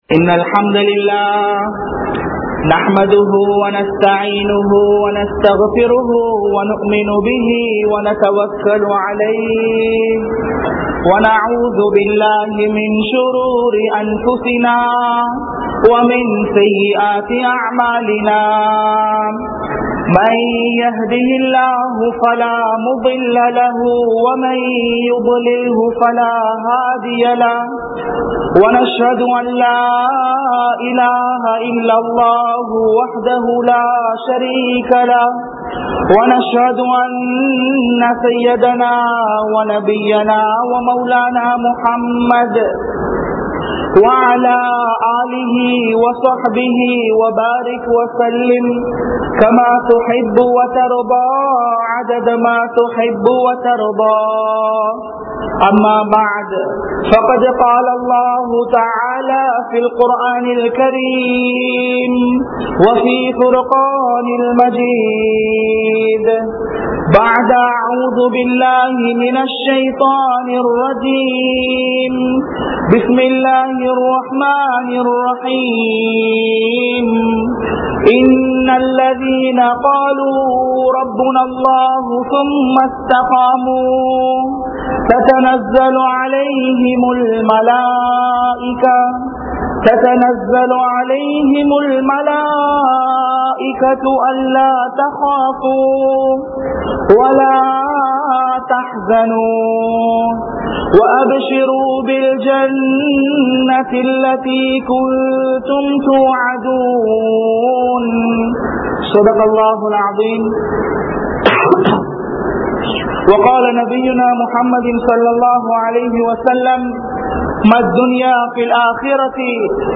Unmaiyaana Muslim (உண்மையான முஸ்லிம்) | Audio Bayans | All Ceylon Muslim Youth Community | Addalaichenai